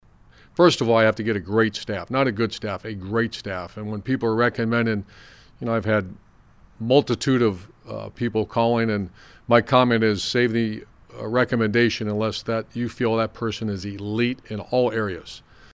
Meyer said in a Jaguars press conference that he wanted to join a winning situation.